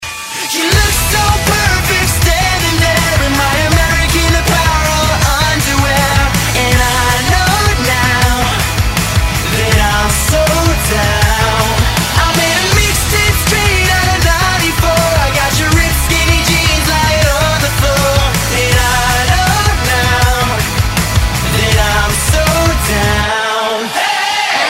Категория: Рок рингтоны